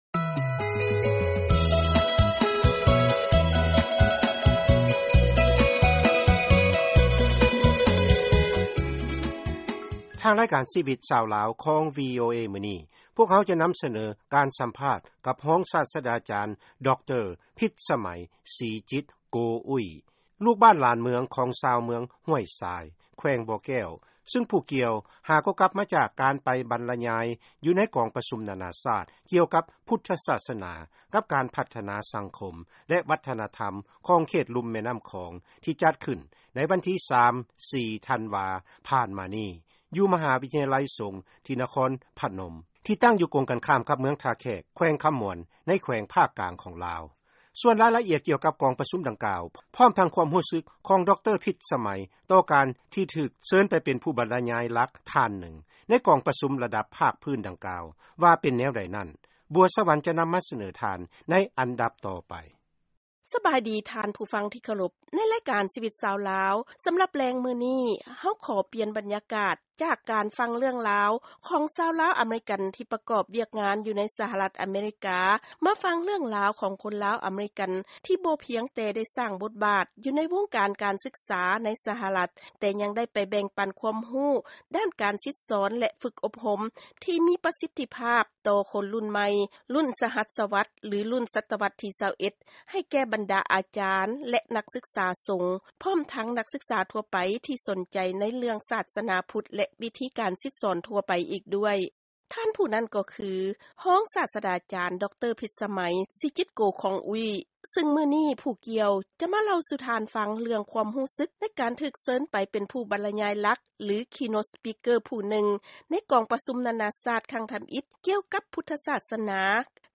ເຊີນຟັງລາຍງານກອງປະຊຸມກ່ຽວກັບພະພຸດທະສາດສະໜາ ແລະການພັດທະນາ ຢູ່ນະຄອນພະນົມ